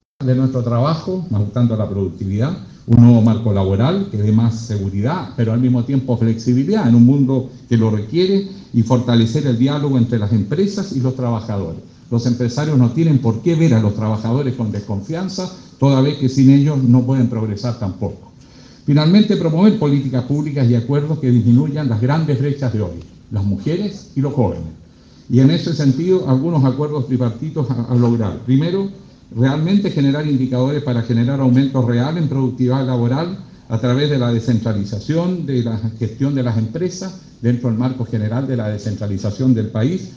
En respuesta a los dichos del exmandatario Ricardo Lagos Escobar, quien señaló hace unos meses que “sin crecimiento… lo demás es música”, el candidato independiente apoyado por el pacto “Fuerza de Mayoría” Alejandro Guillier señaló hoy en la CUT que: “Lo más importante es la música, no basta solo crecer, la seguridad social es la única base de la legitimidad y estabilidad política y social”.